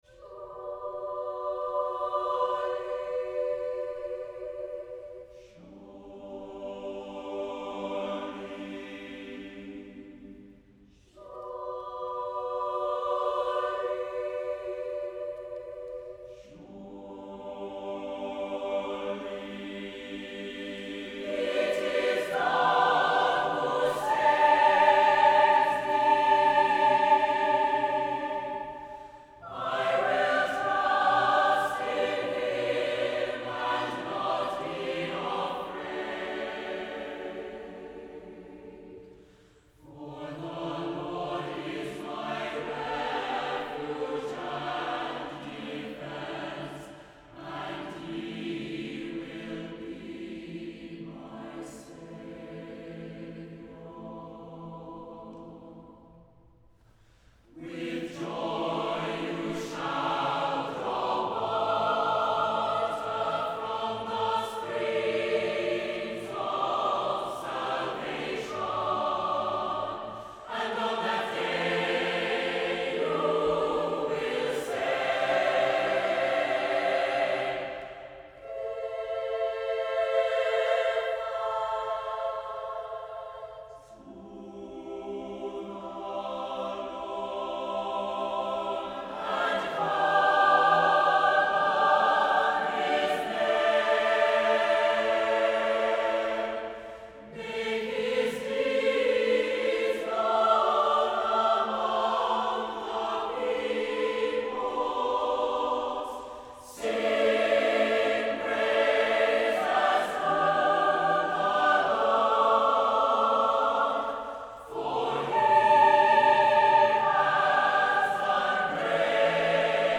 motet